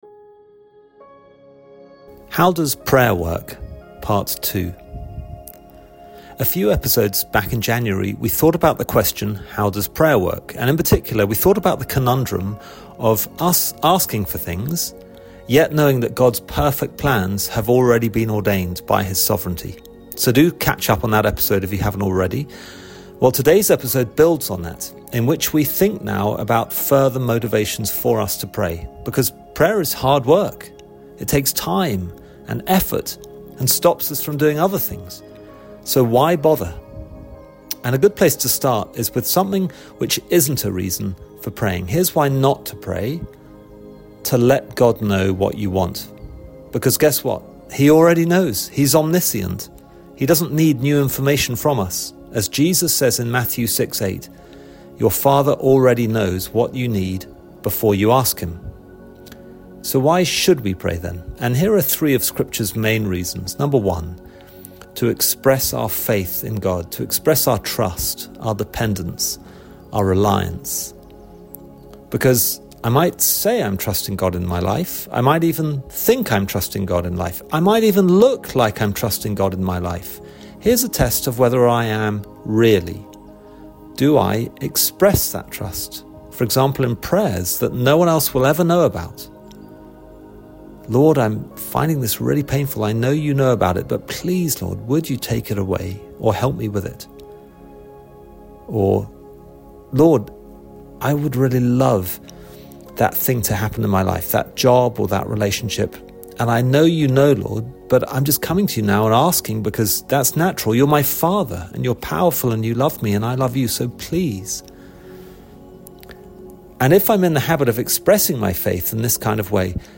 2025 Current Sermon How Does Prayer Work?